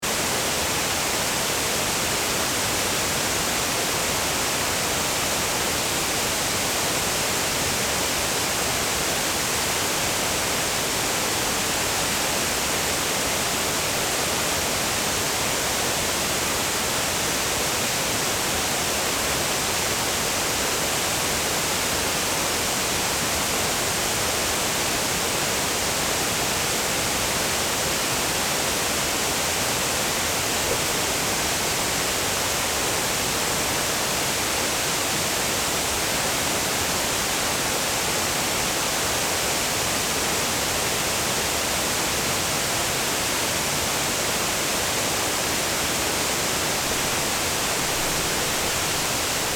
Waterfall in central Norrköping (1)